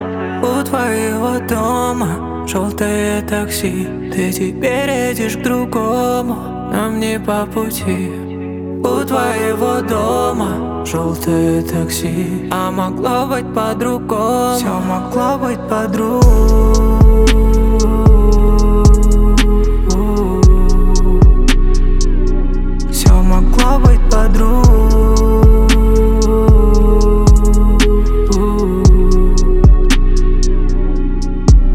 поп
грустные